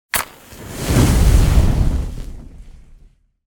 fire_start_burning_to_end1.ogg